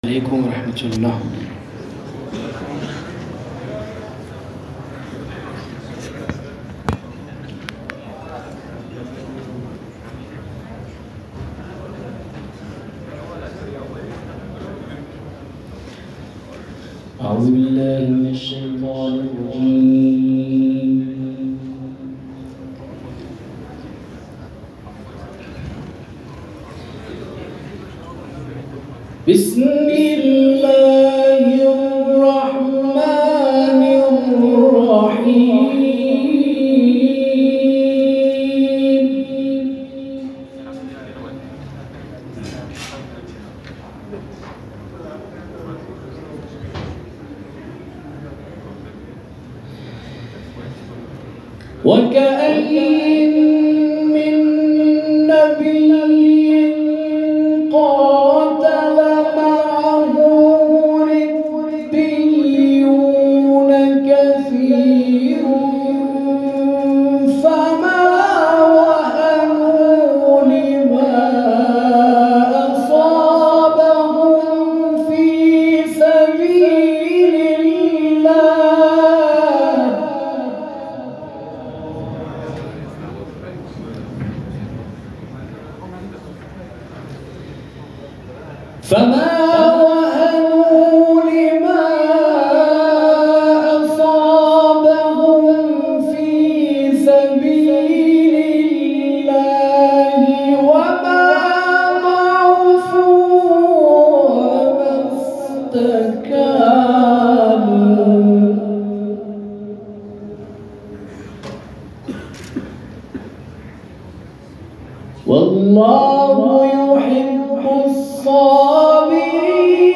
محفل انس و تدبر در قرآن کریم بعدازظهر امروز ۲۸ آبان‌ماه در دانشگاه صنعتی امیرکبیر برگزار شد.
تلاوت